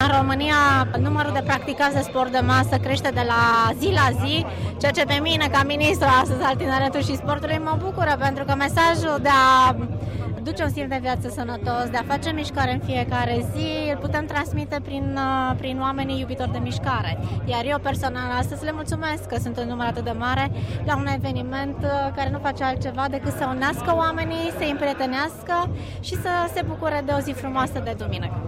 La eveniment este prezenta si Gabriela Szabo, ministrul tineretului si sportului: